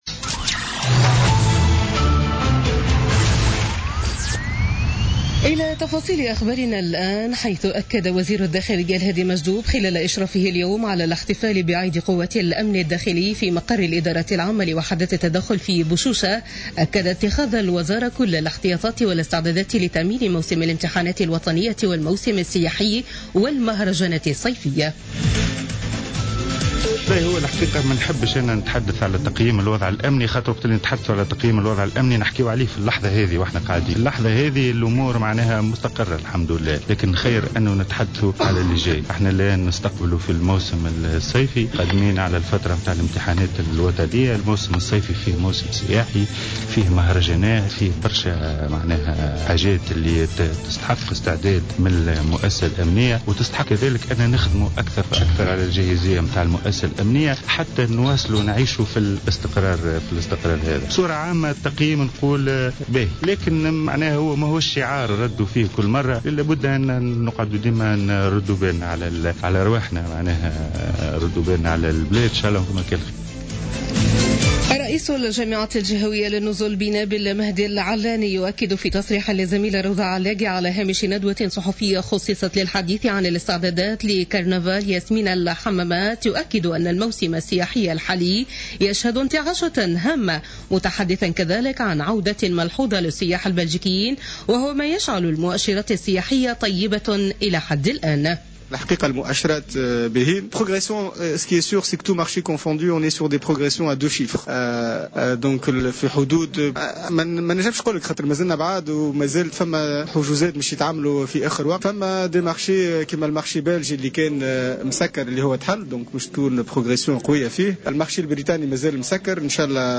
نشرة أخبار السابعة مساء ليوم الخميس 13 أفريل 2017